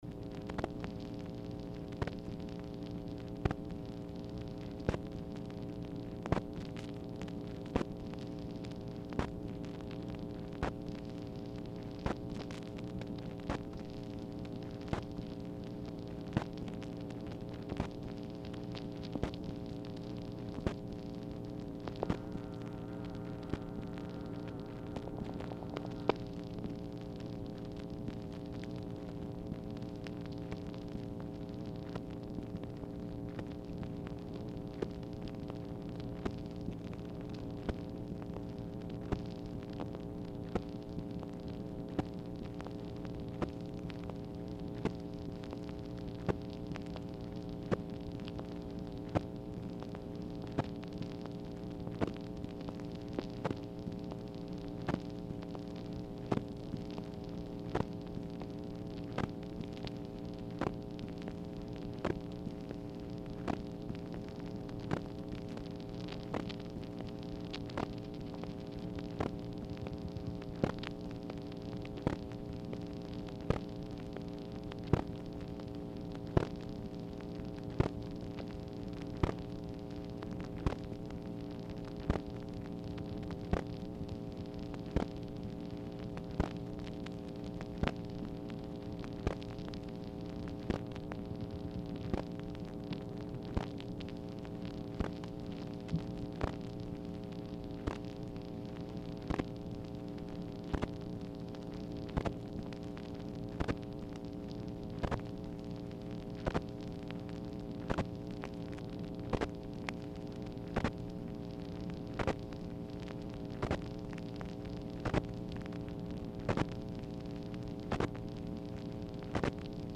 Telephone conversation # 4994, sound recording, MACHINE NOISE, 8/17/1964, time unknown | Discover LBJ
Format Dictation belt
Specific Item Type Telephone conversation